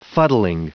Prononciation du mot fuddling en anglais (fichier audio)
Prononciation du mot : fuddling